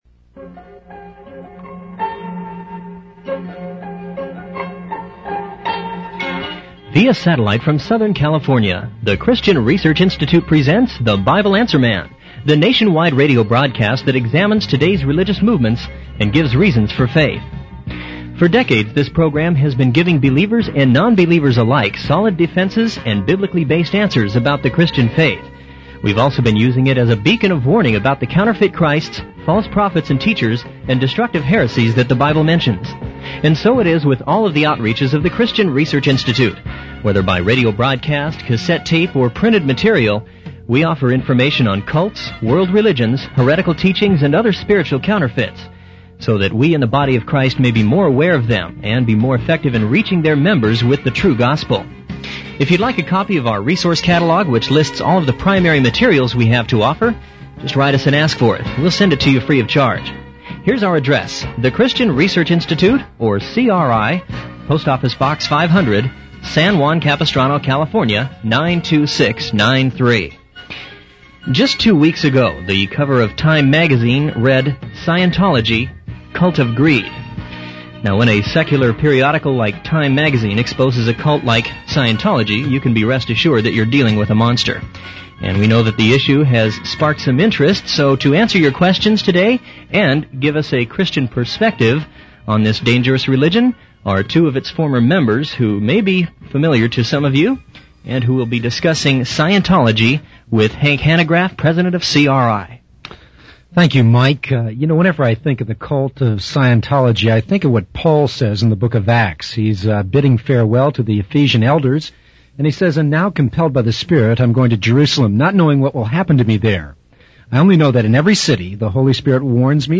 In this sermon, the speaker shares their personal experience of being involved in a manipulative and brainwashing religious group for five years.